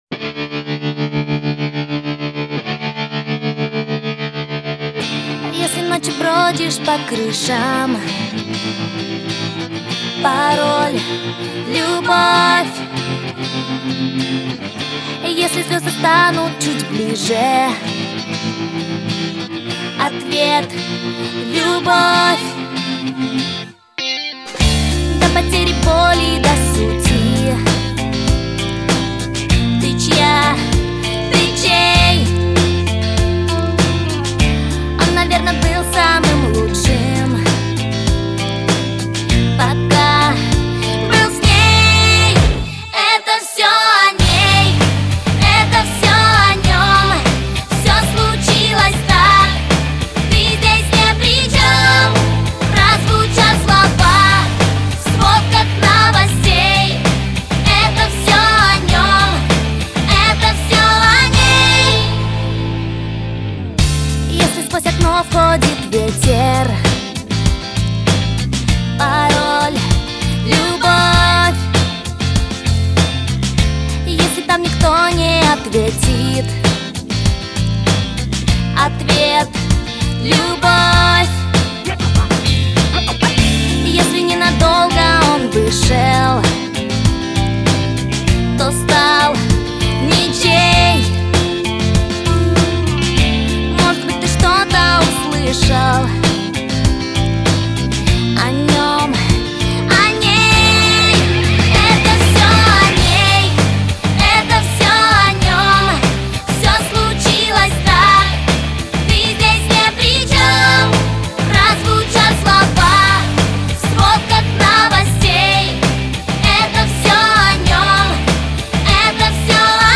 Стиль: Pop